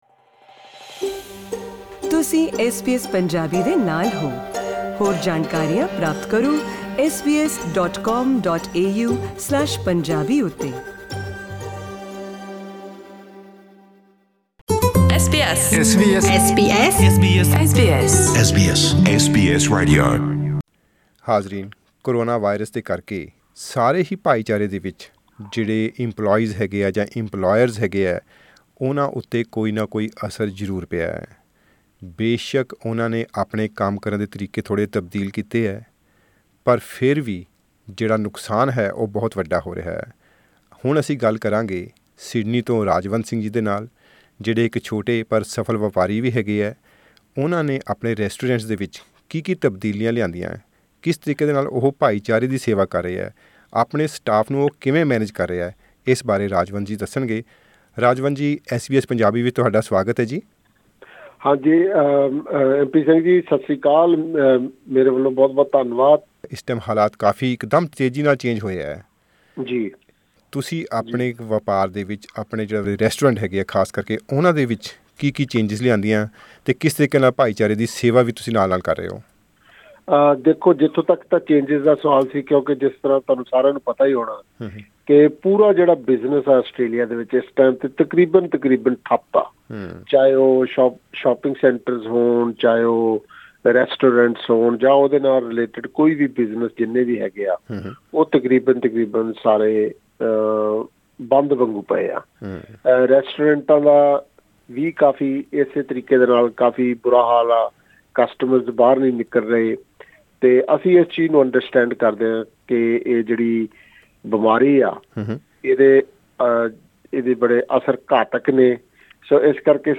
Click on the player above to listen to the interview in Punjabi.